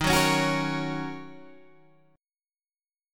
D# Minor 7th